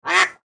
Duck-quack-sound-effect.mp3